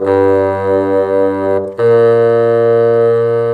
Rock-Pop 01 Bassoon 05.wav